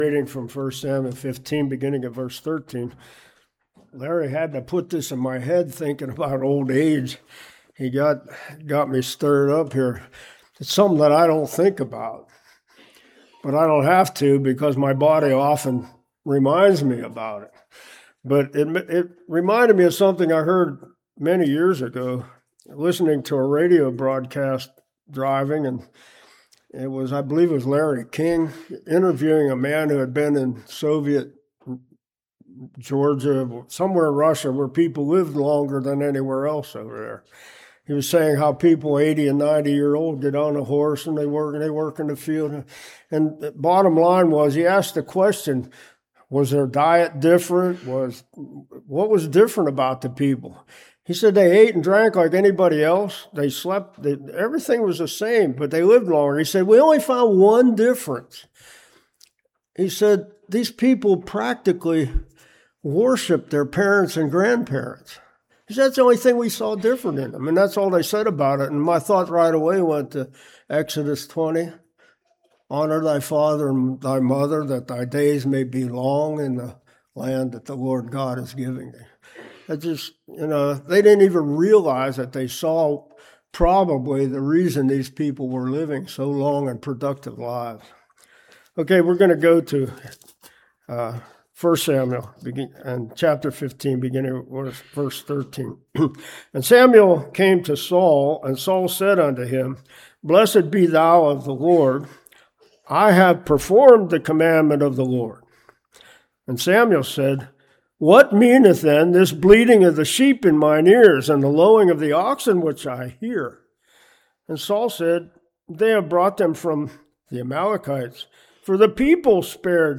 1 Samuel 15:13-31 Service Type: Morning Partial obedience is not any different than disobedience.